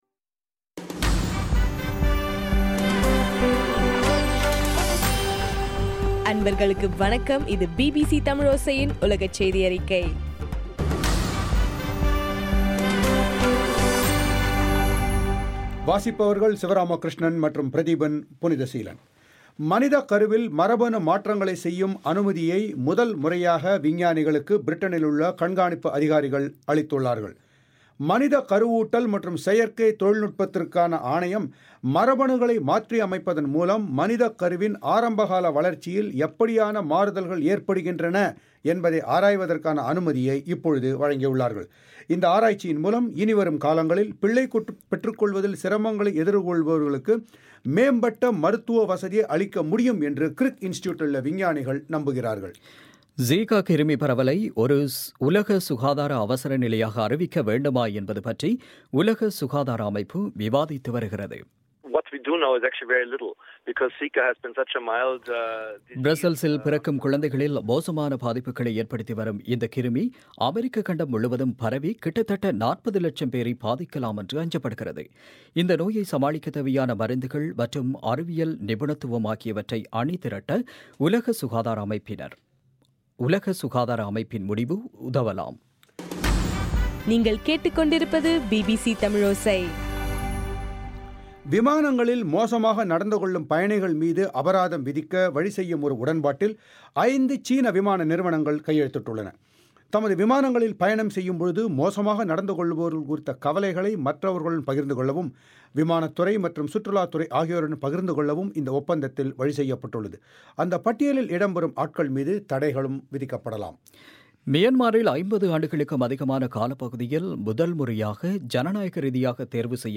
பிப்ரவரி 1, 2016 பிபிசி தமிழோசையின் உலகச் செய்திகள்